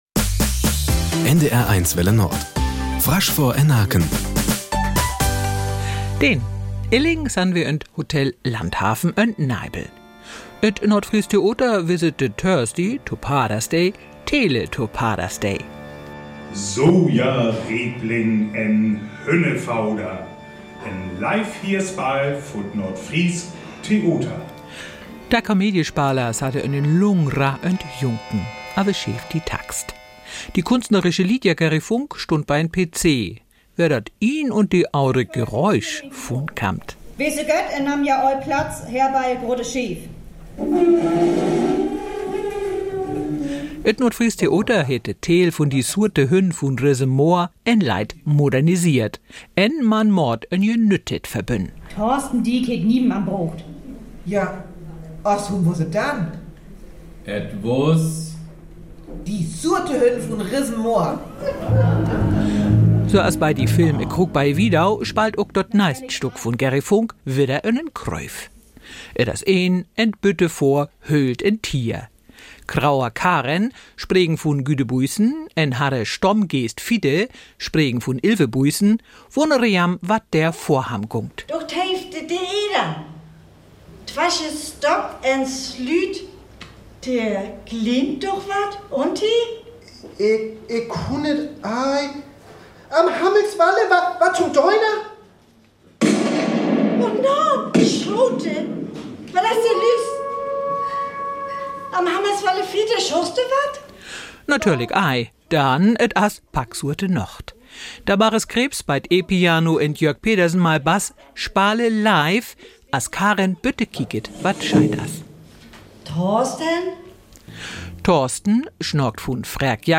Friesisch für alle: Live-Hörspiel